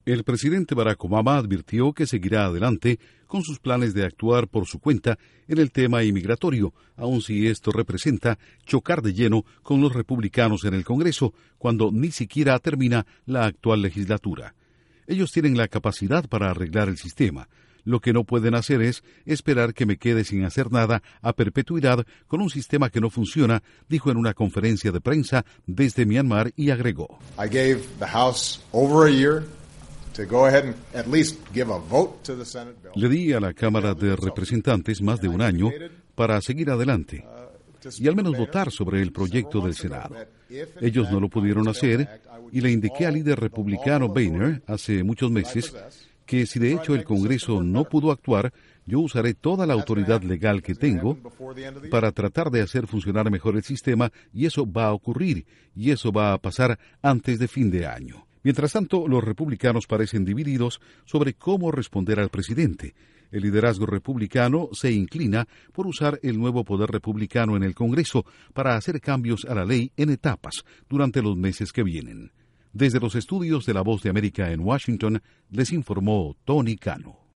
El presidente Barack Obama advierte que seguirá adelante con sus planes sobre inmigración, aunque difiera con los republicanos. Informa desde los estudios de la Voz de América en Washington